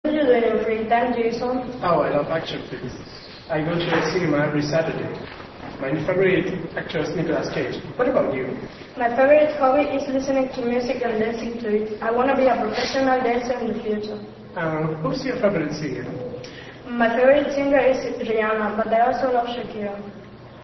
Everyday conversations
Chico y chica de pié una frente a otro mantienen una conversación
Chico y chica de pié charlando.